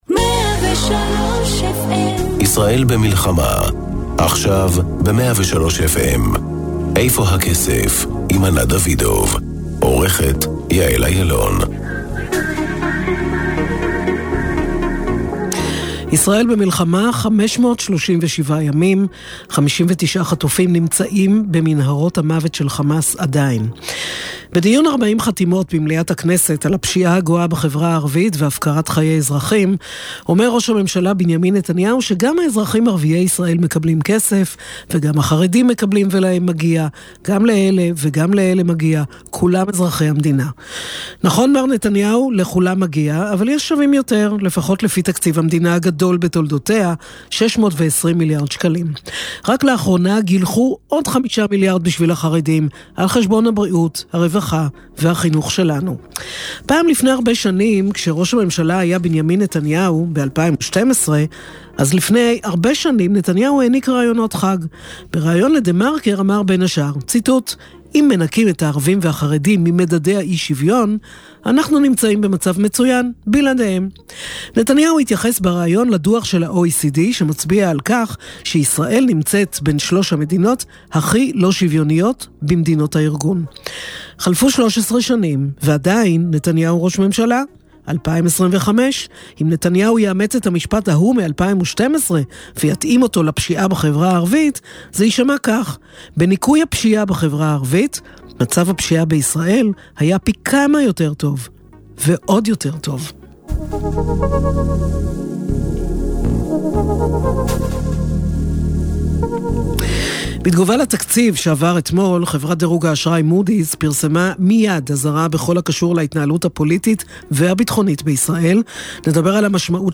לצד הכותרות הכלכליות, מביאה התוכנית ראיונות עם בכירי המשק, תחקירי צרכנות פיננסית, טורים אישיים שתוקפים את נושאי הכלכלה מזוויות שונות, ופינות בנושאי טכנולוגיה ואפילו טיולים ופנאי.